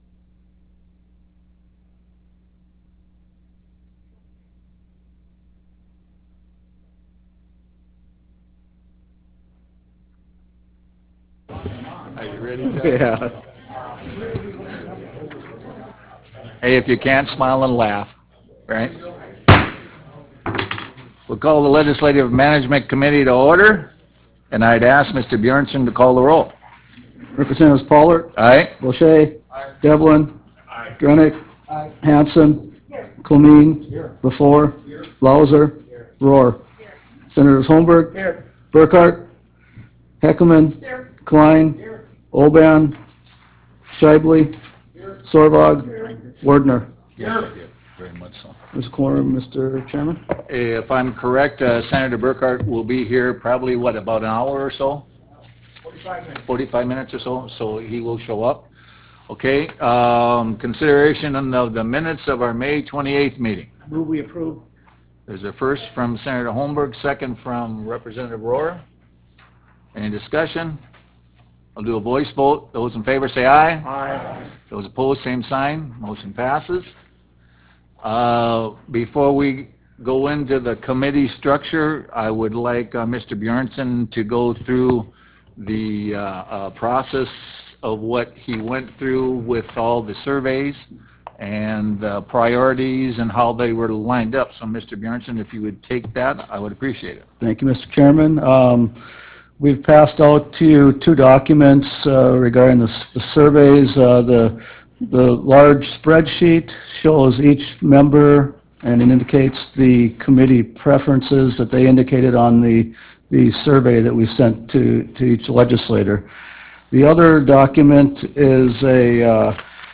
Roughrider Room State Capitol Bismarck, ND United States
Meeting Audio